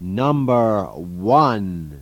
"One" (1) in een countdown